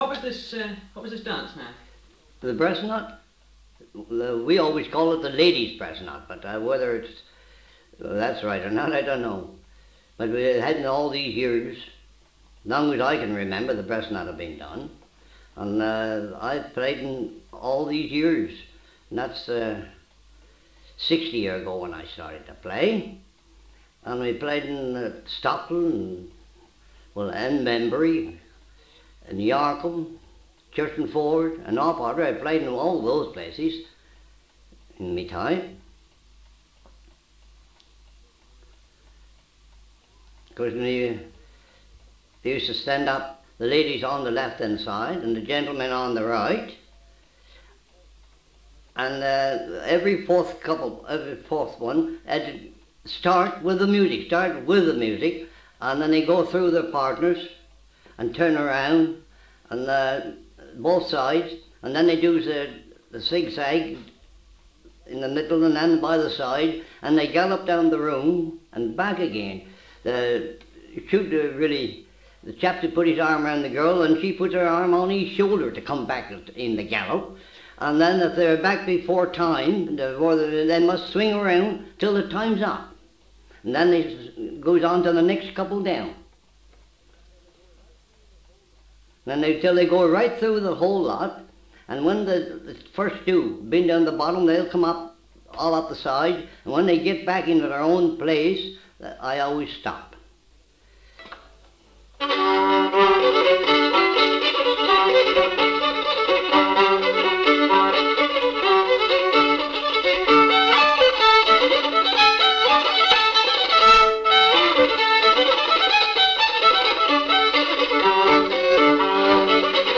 Please enjoy the music and the local Devon accent.
03 The Ladies Breast Knot (Talking).m4a